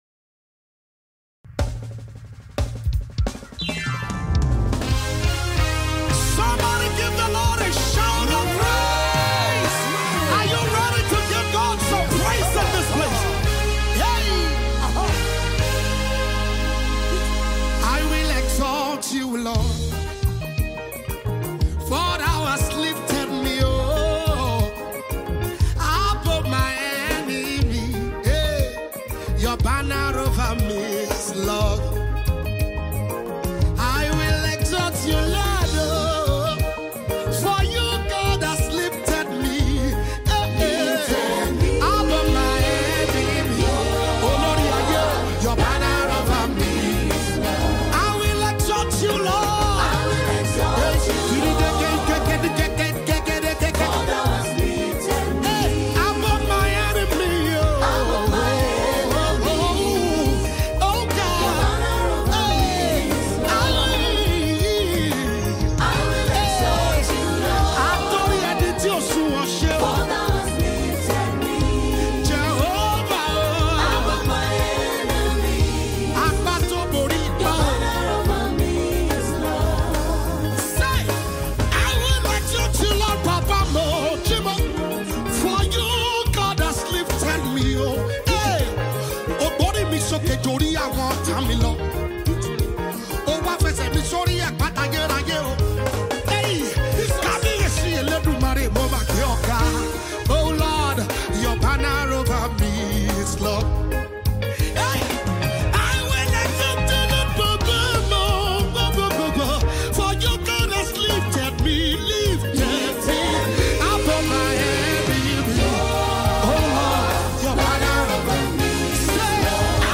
Nigerian Christian/Gospel worshipper
soul-lifting selection